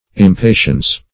Impatiens \Im*pa"ti*ens\ ([i^]m*p[=a]"sh[i^]*[e^]nz), prop. n.